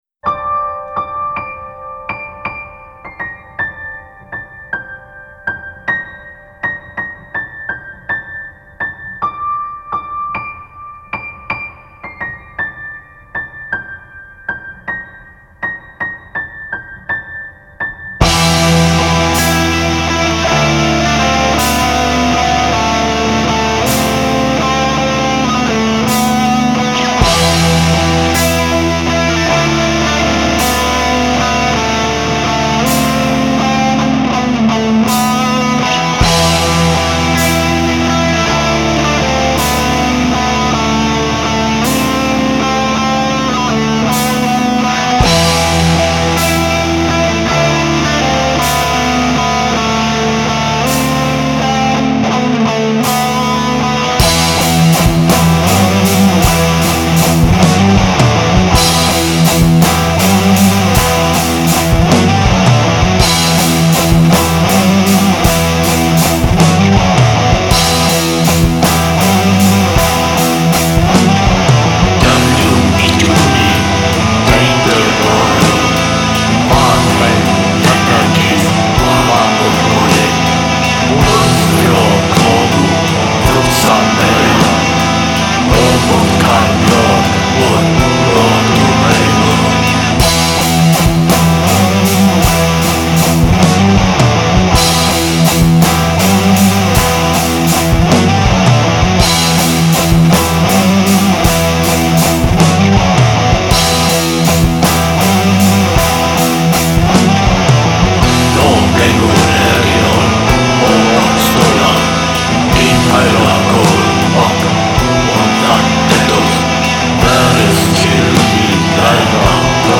Viking Metal black metal